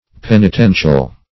Penitential \Pen`i*ten"tial\, a. [Cf. F. p['e]nitentiel.]